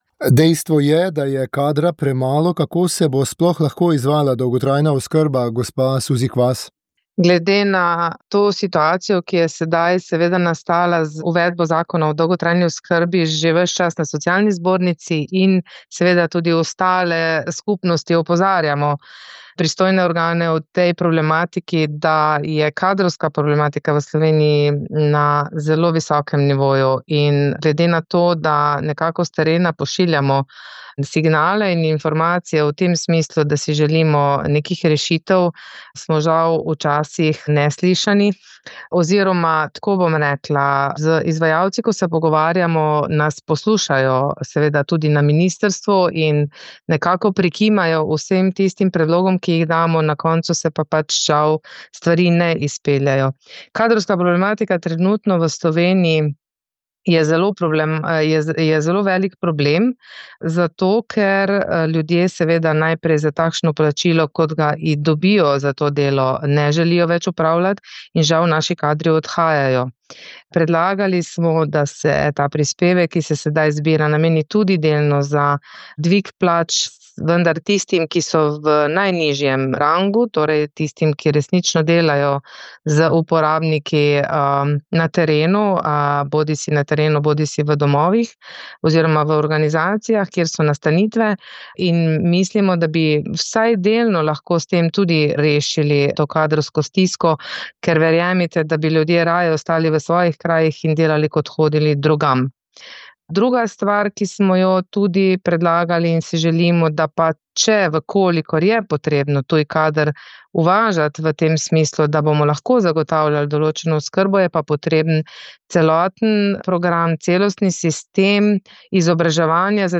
Kaj se dogaja v Gibanju svoboda smo vprašali političnega komentatorja